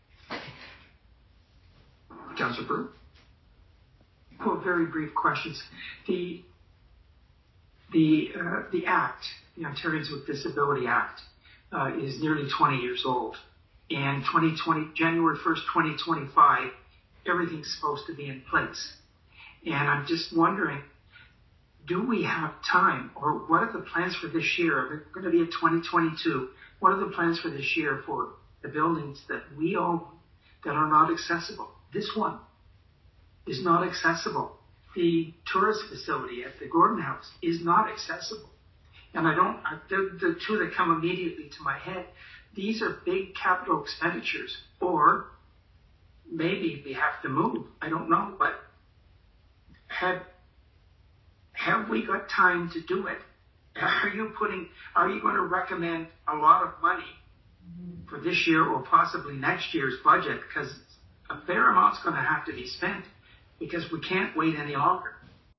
Then-Councillor Prue made these comments at a December 2021 council meeting: